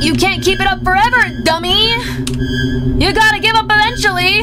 Worms speechbanks
Fatality.wav